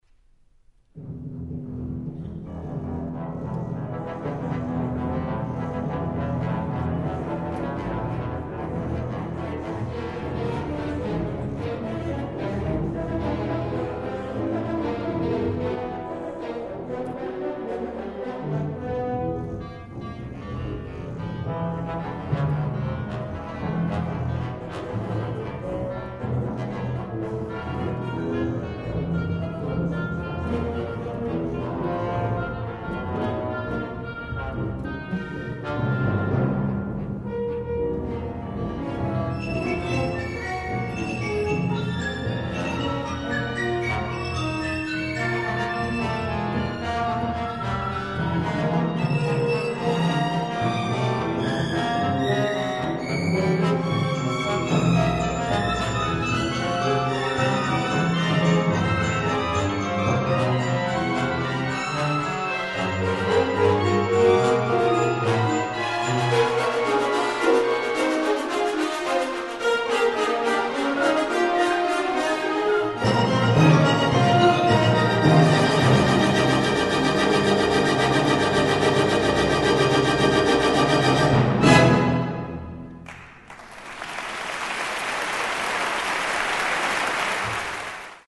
short orchestral composition